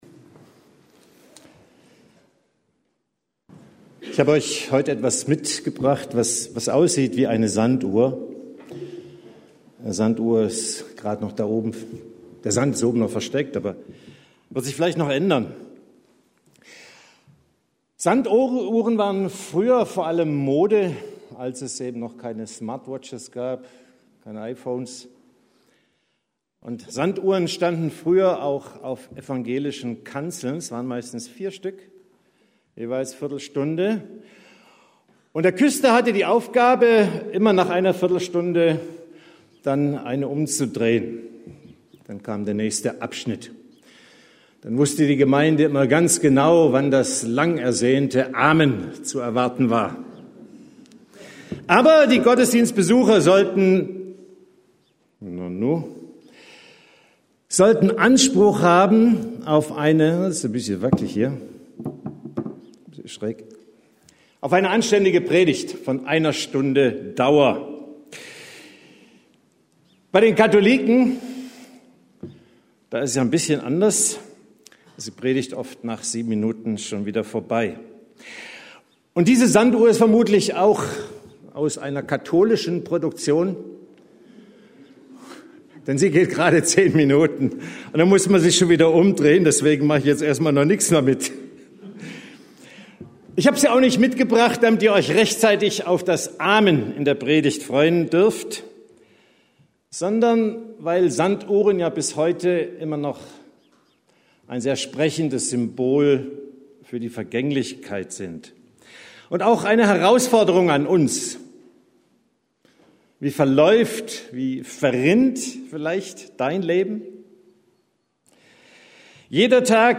Zeitenwende – Predigten: Gemeinschaftsgemeinde Untermünkheim